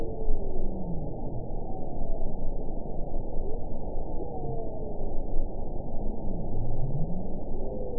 event 922418 date 12/31/24 time 21:27:35 GMT (5 months, 2 weeks ago) score 9.20 location TSS-AB06 detected by nrw target species NRW annotations +NRW Spectrogram: Frequency (kHz) vs. Time (s) audio not available .wav